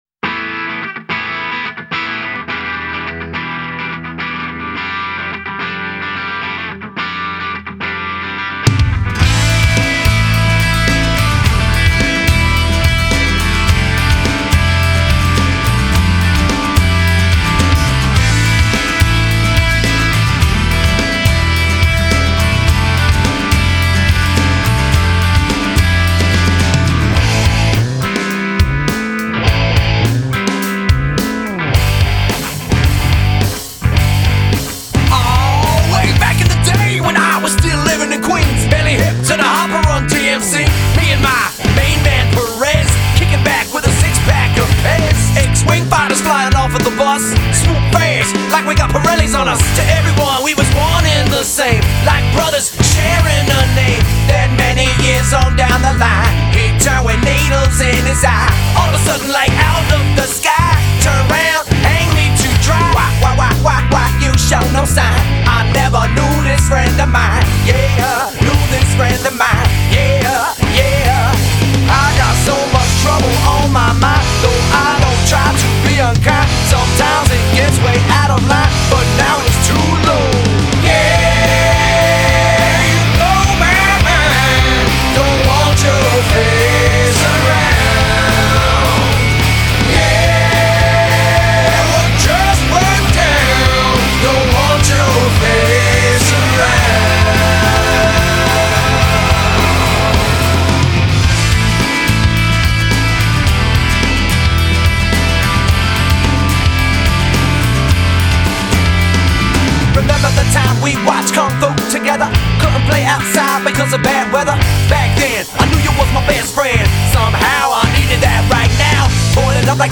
Genre: Alt.Rock.